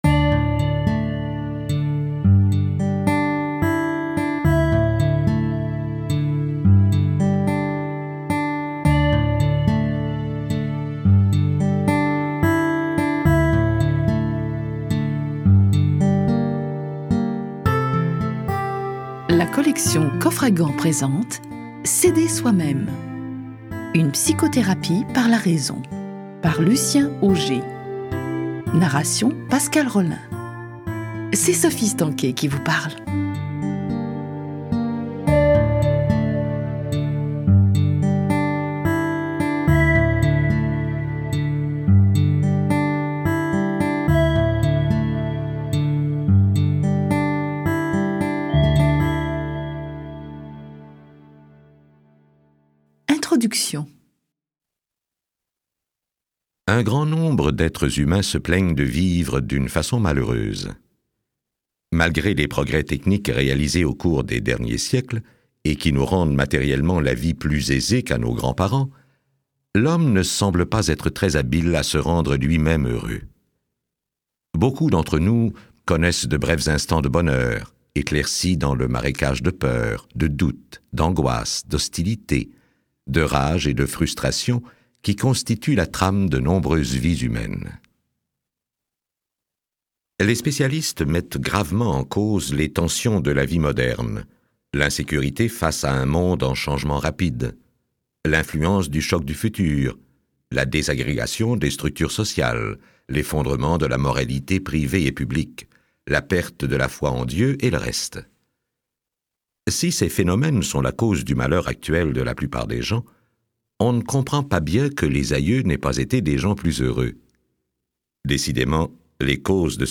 Get £2.29 by recommending this book 🛈 Ce livre audio se propose d’arriver à une explication vraiment satisfaisante de la présence des phénomènes de contrariété dans la vie des hommes. Il présente une méthode claire et simple pour se libérer des troubles émotifs qui viennent compromettre le bonheur humain.